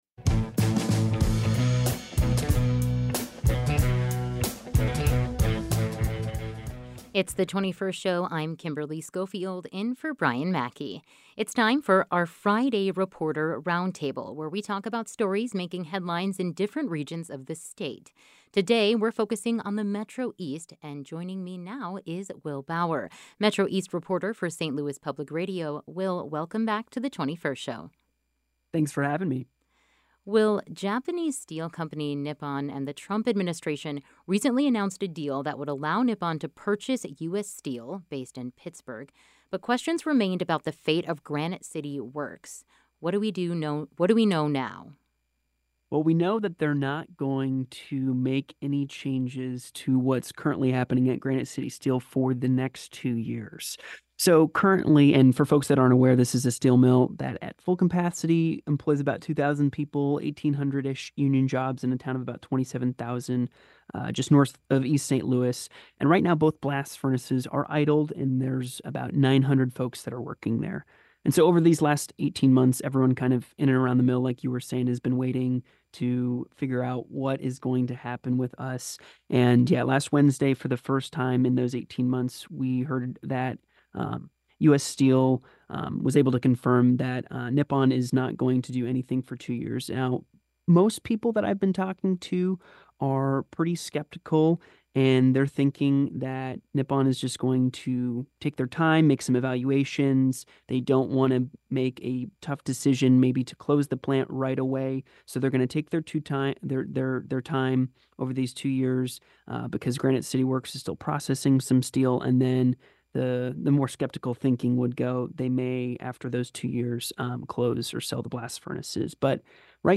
For this week's Friday Reporter Roundtable, we're focusing on the Metro East. Japanese steel company Nippon and the Trump Administration recently announced a deal that would allow Nippon to purchase U.S. Steel, based in Pittsburg, but question remained about the fate of Granite City Works. Also, the Army Corps of Engineers in Madison County are addressing a flood prevention system that hasn't worked as well as it intended.